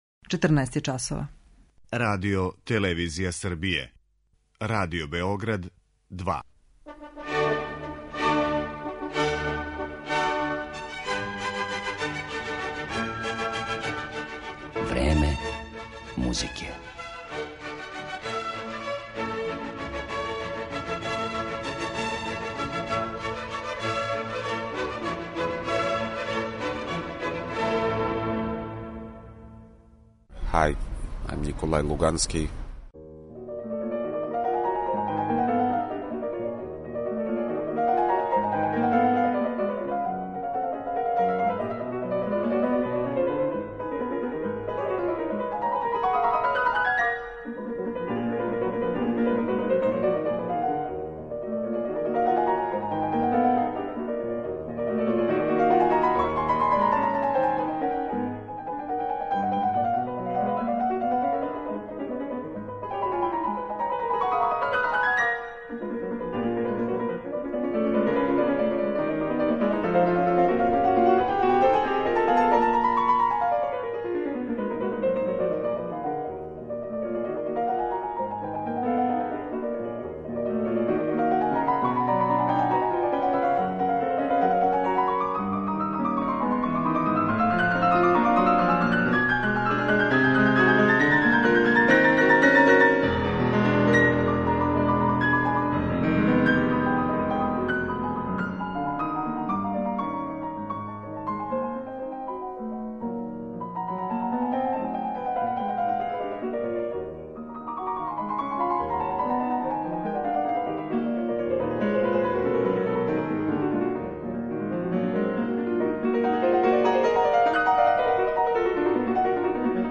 Овог уметника слушаћемо како изводи дела Рахмањинова, Шопена, Шумана, Бетовена и Прокофјева, а представићемо га и кроз ексклузивни интервју снимљен с њим 2017. године.